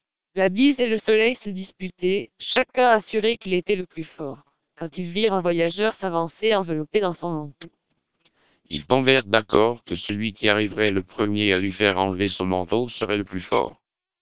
Also, all independent listeners preferred SPR, noting its significantly higher speech quality and intelligibility.